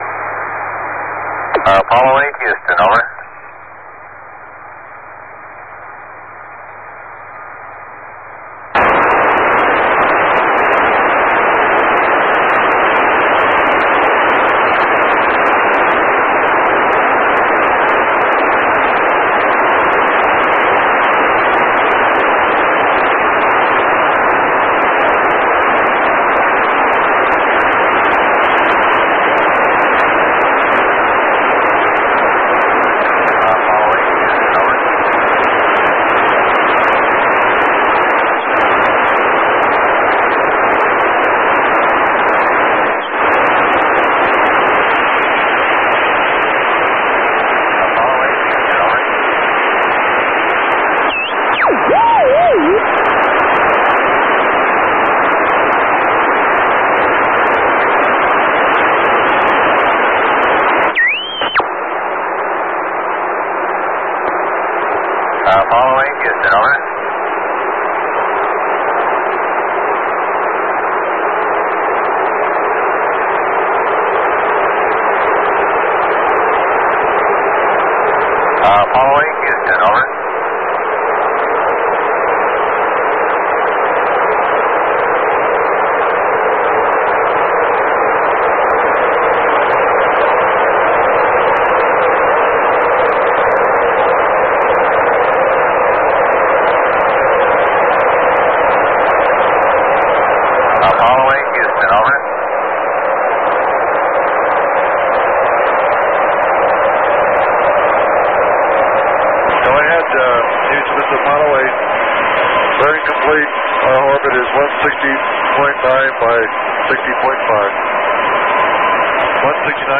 Honeysuckle Creek Tracking Station.